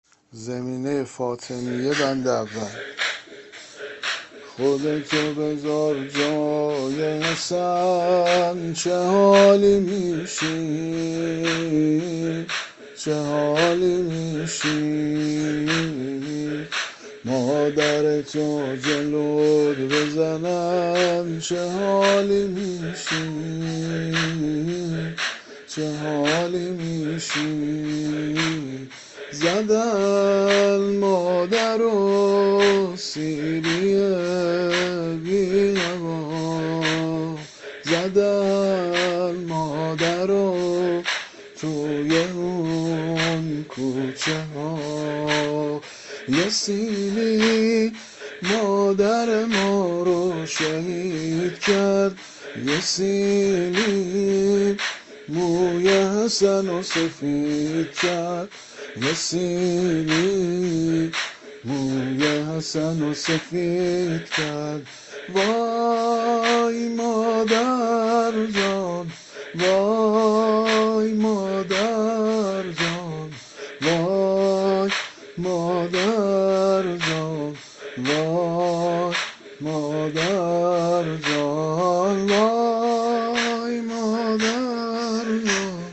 زمینه ایام_فاطمیه (روضه کوچه) -(خودتو بزار جای حسن (چه حالی میشی)۲)